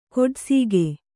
♪ koḍsīge